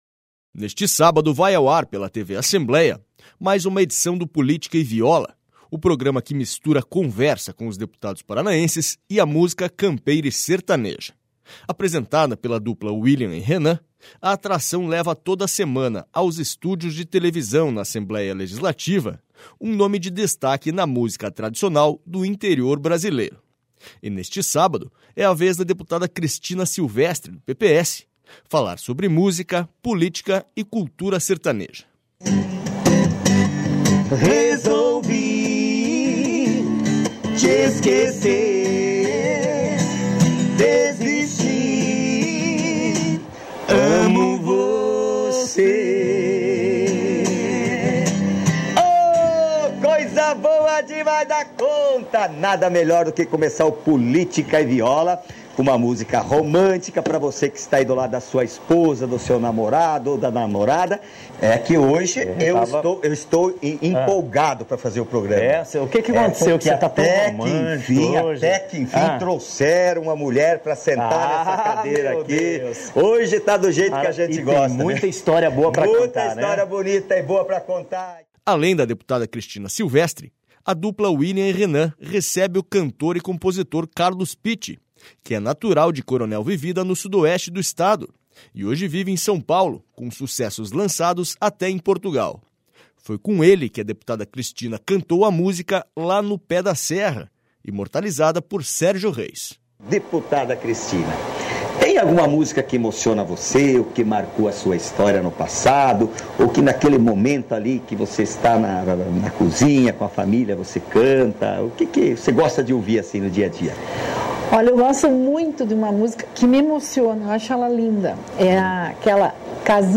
Neste sábado vai ao ar pela TV Assembleia mais uma edição do Política e Viola, o programa que mistura conversa com os deputados paranaenses e a música campeira e sertaneja.